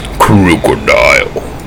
Audio / SE / Cries / KROOKODILE.mp3